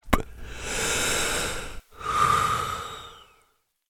Вдохнул затем выдохнул